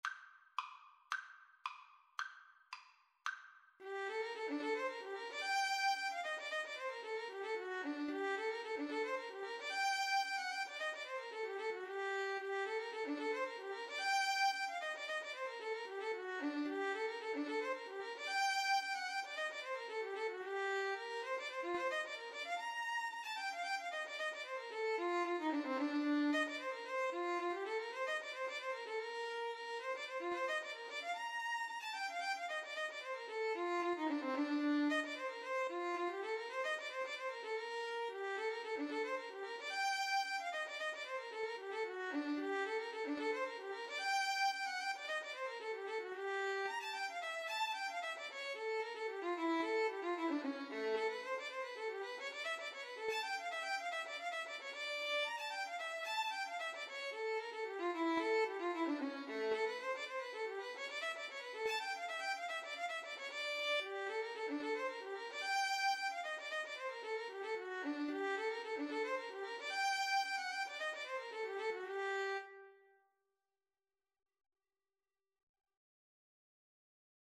2/2 (View more 2/2 Music)
Classical (View more Classical Violin-Cello Duet Music)